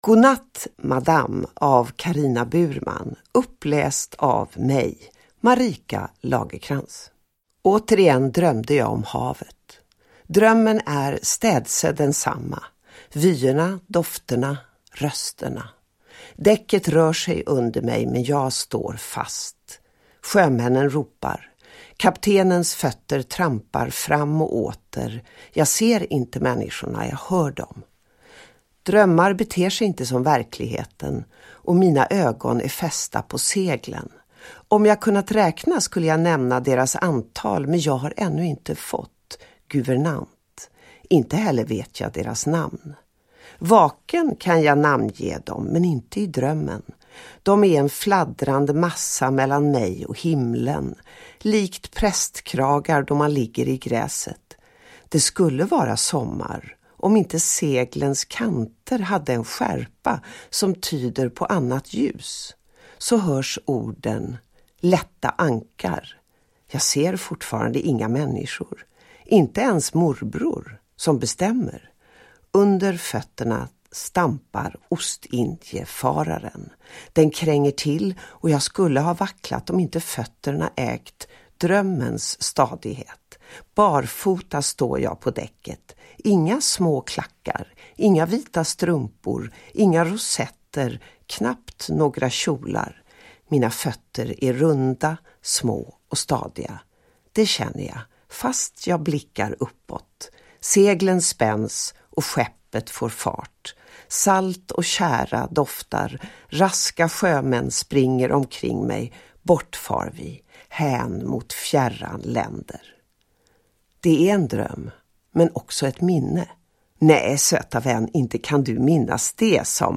Uppläsare: Marika Lagercrantz
Ljudbok